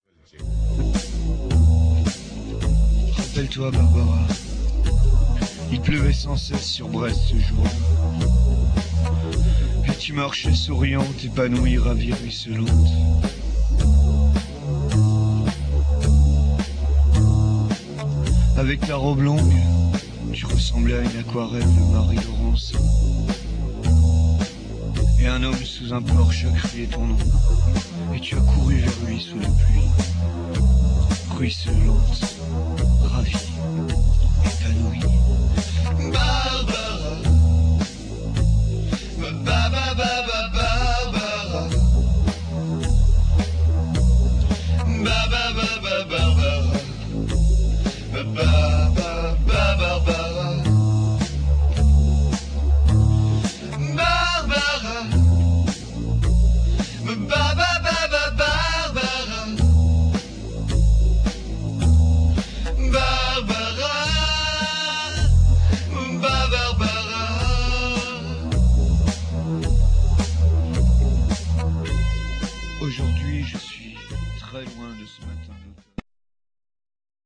Par leurs reprises
c'est peut-être le sirtaki ...